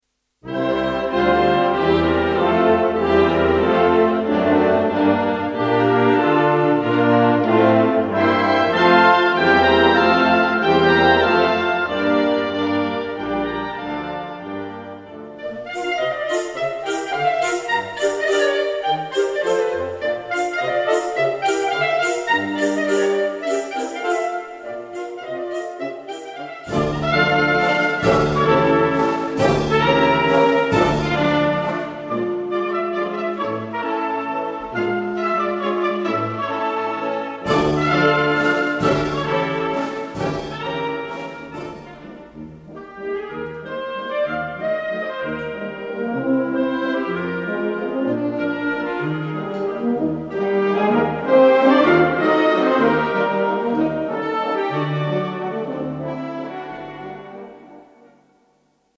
• Besetzung: Blasorchester